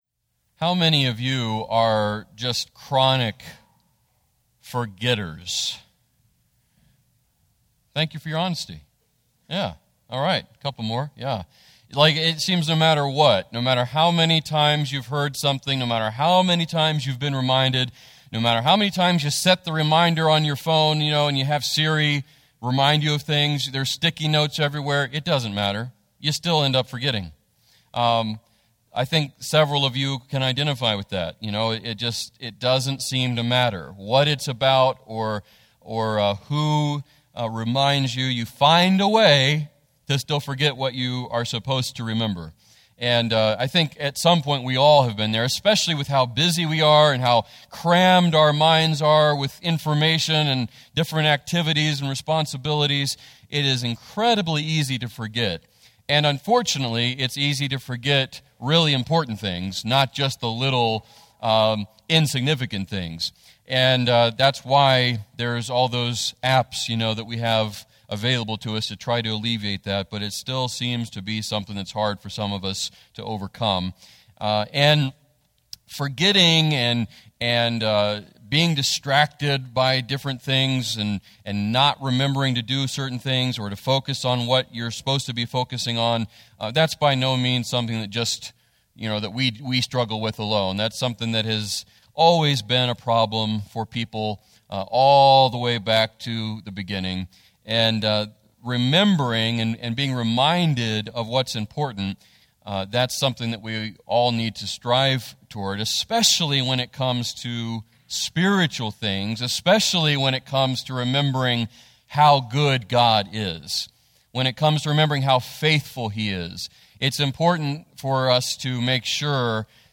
FBC Sermon Audio podcast
Weekly messages from the pulpit of Faith Baptist Church, Prosperity, WV.